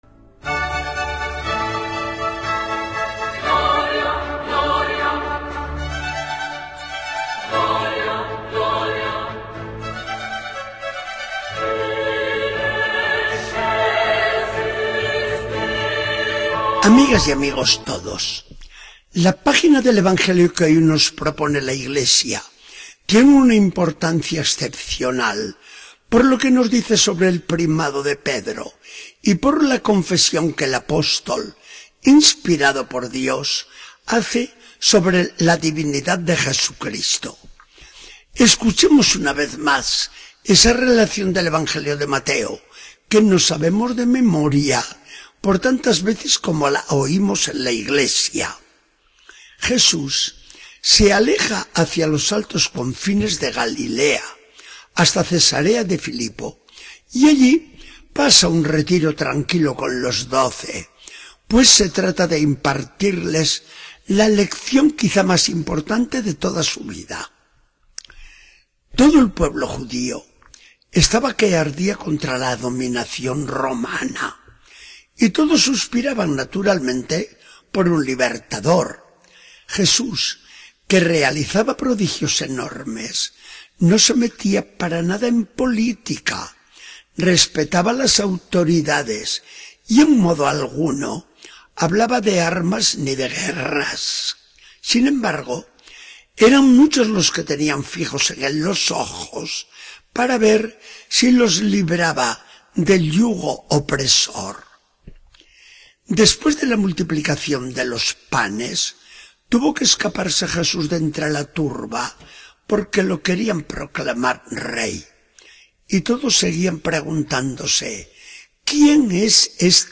Charla del día 24 de agosto de 2014. Del Evangelio según San Mateo 16, 13-20.